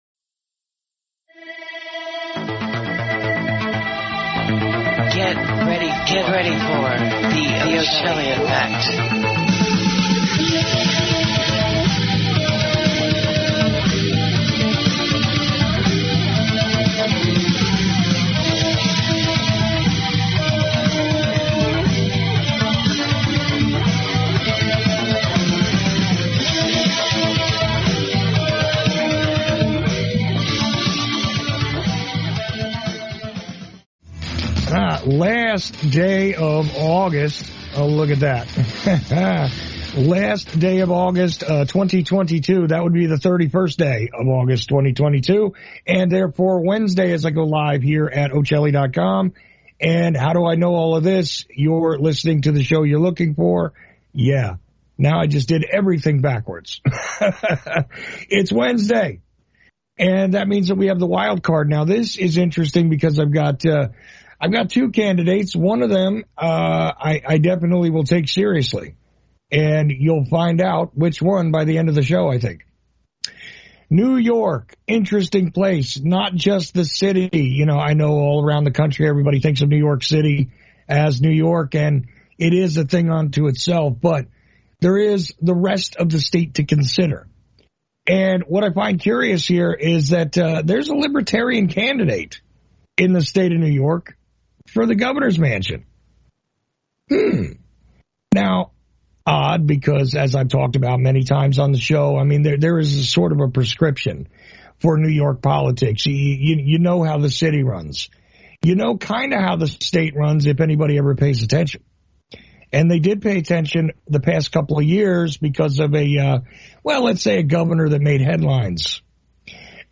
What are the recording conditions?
This podcast contains the first hour of Wednesday Night’s broadcast. The two-party stranglehold on New York state politics has resulted in one-party domination of all things in the Empire State.